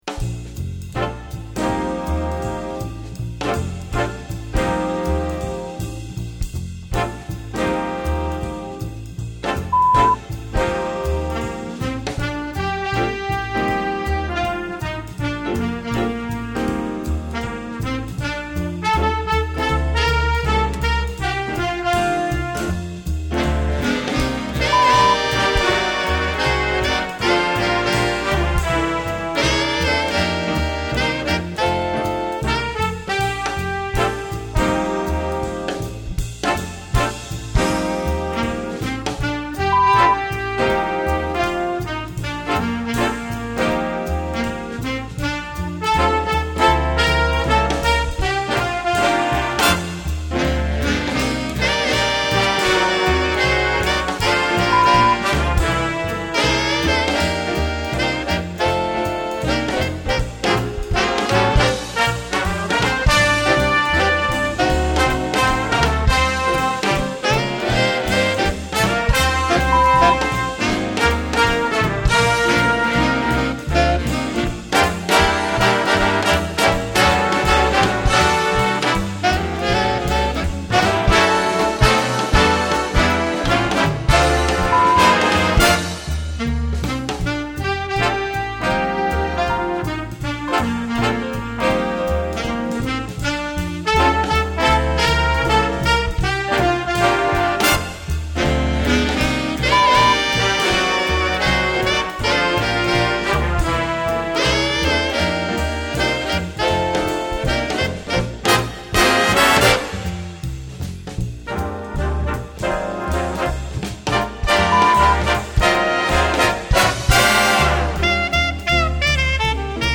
Répertoire pour Jazz band - Jazz Band